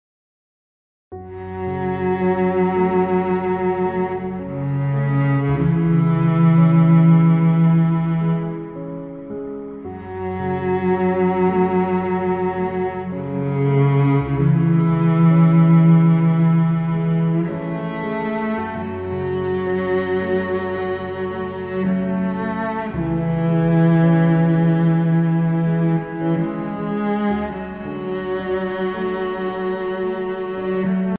Heavenly Violin & Cello Instrumentals